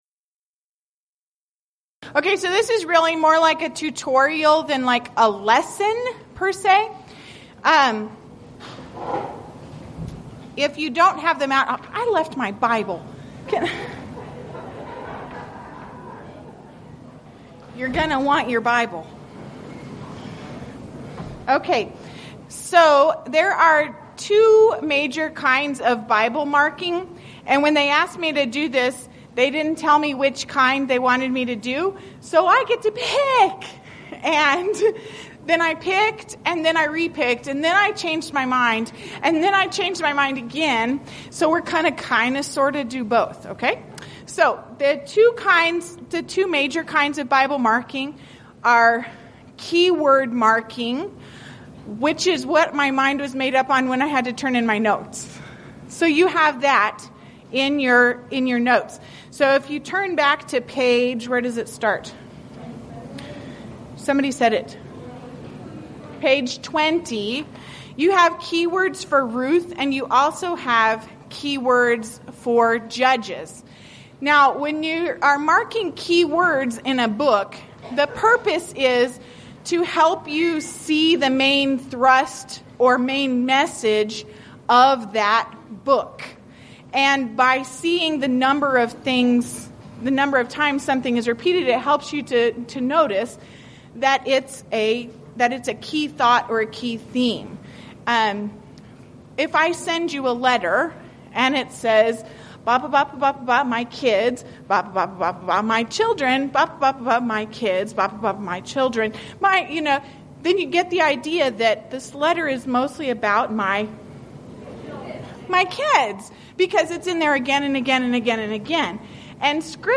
Event: 7th Annual Texas Ladies in Christ Retreat Theme/Title: Studies in Ruth & Judges
Ladies Sessions